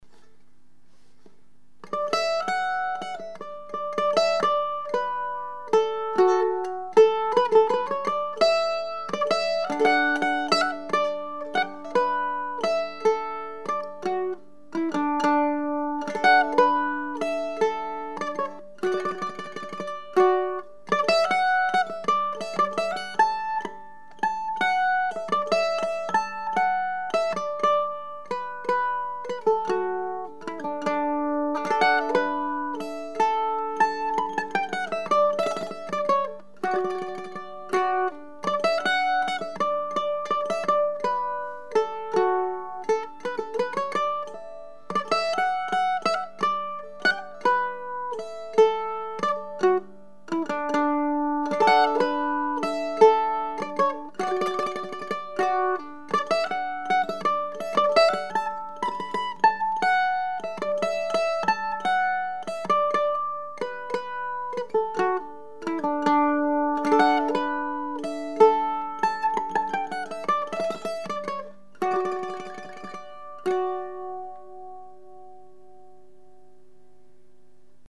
Classical Flattop Mandolin #341  $3200 (includes case)
The top is about 30% lighter and gives more volume and improved tone.
A clean, clear evenly balanced sound.